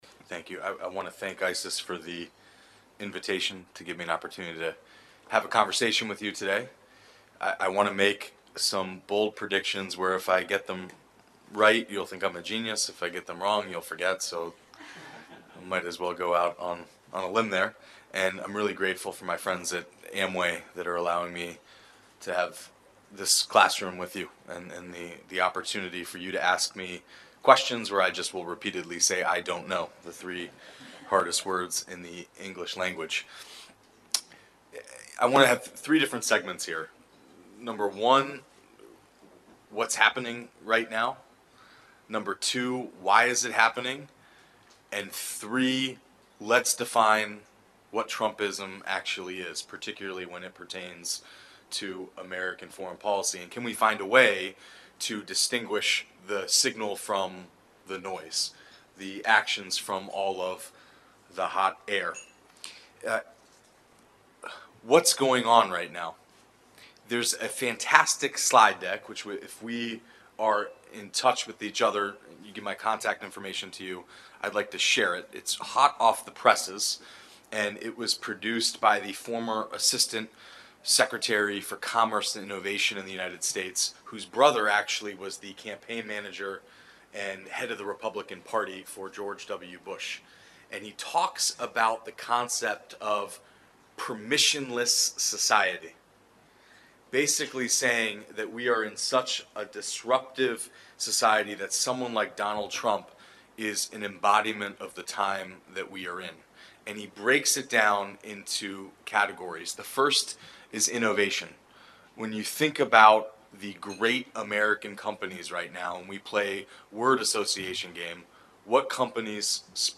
Venue Conference Room, ISIS Malaysia